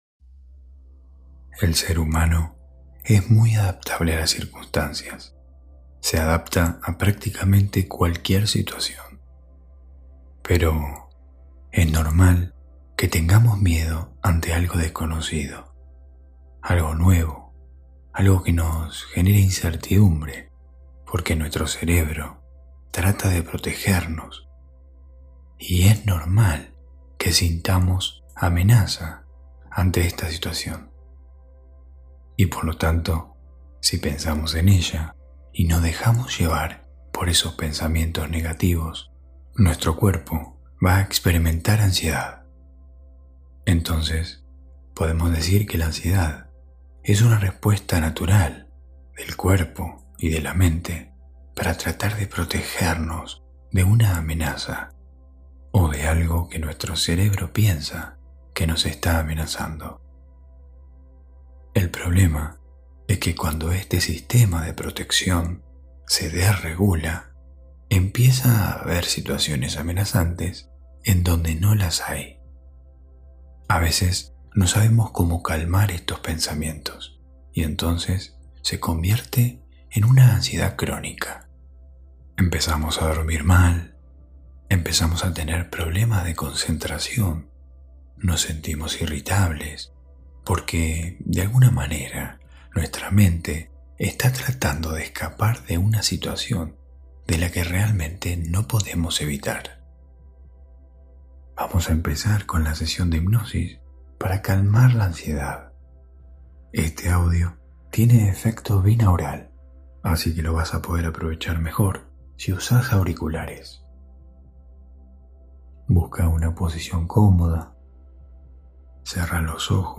Hipnosis guiada para dormir profundamente.